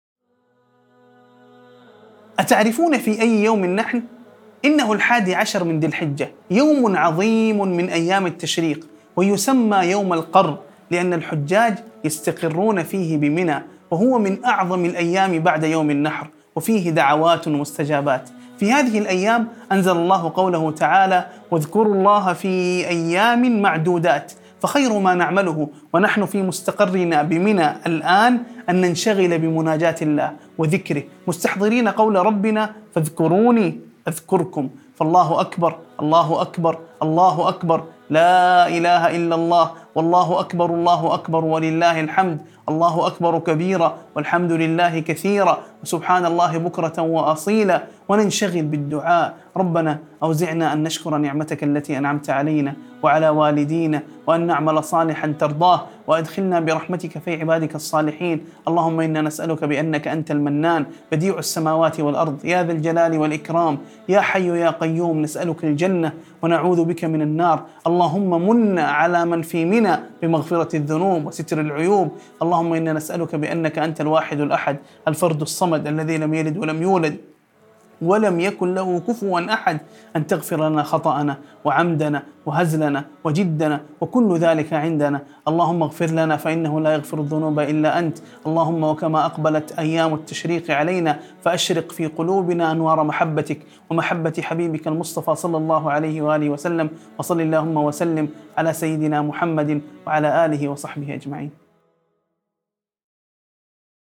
مقطع صوتي مليء بالدعاء والمناجاة في يوم من أيام التشريق المباركة، يحث على الإكثار من ذكر الله والتسبيح والاستغفار. يتضمن أدعية مؤثرة للمغفرة وستر العيوب والشكر لله على نعمه، والدعاء للوالدين ولجميع المسلمين.